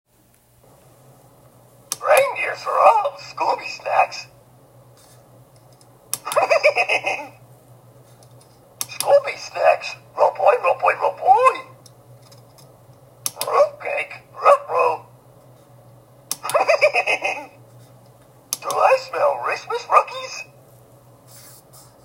Scooby-Doo Talking Christmas Ornament, Hallmark 2008